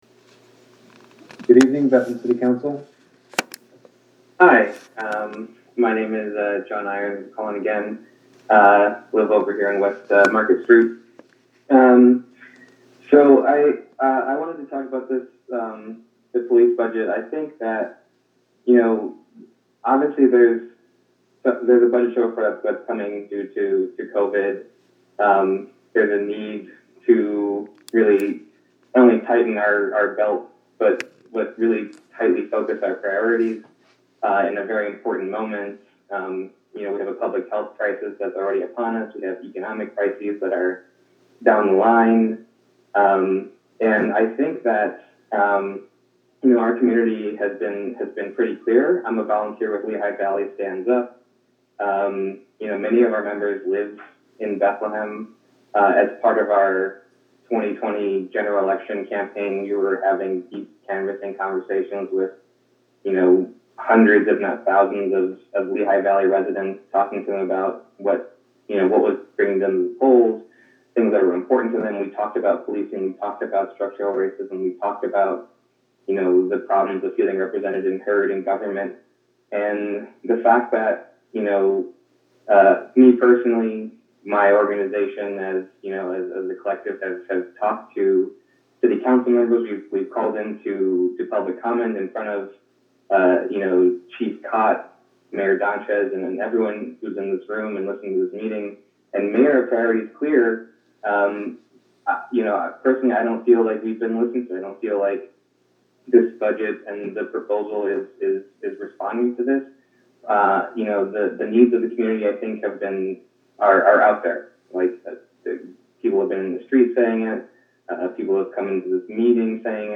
Budget Hearing November 9 video
public comment, begin min. 3:53:49